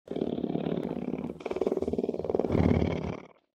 دانلود صدای شیر 4 از ساعد نیوز با لینک مستقیم و کیفیت بالا
جلوه های صوتی
برچسب: دانلود آهنگ های افکت صوتی انسان و موجودات زنده دانلود آلبوم صدای انواع شیر از افکت صوتی انسان و موجودات زنده